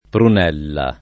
vai all'elenco alfabetico delle voci ingrandisci il carattere 100% rimpicciolisci il carattere stampa invia tramite posta elettronica codividi su Facebook prunella [ prun $ lla ] o brunella [ brun $ lla ] s. f. («erba»)